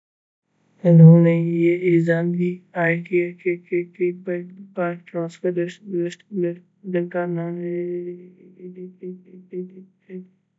deepfake_detection_dataset_urdu / Spoofed_Tacotron /Speaker_15 /104.wav